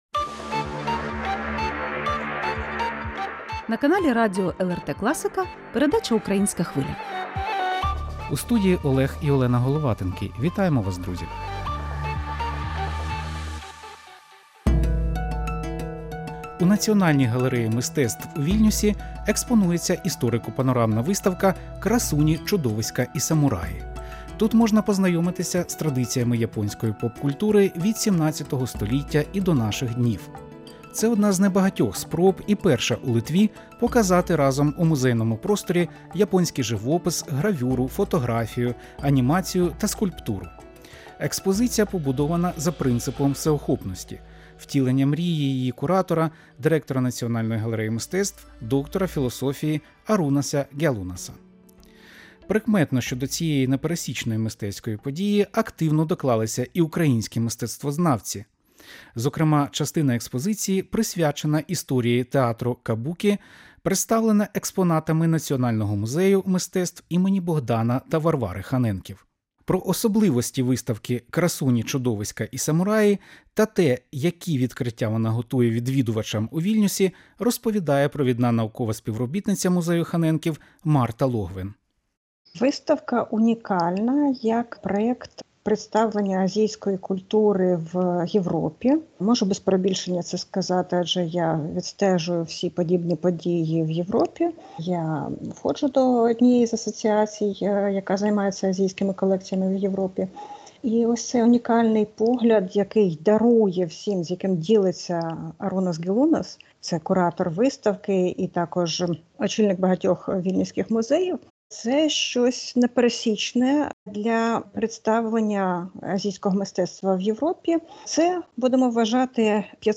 Інтерв’ю з експертом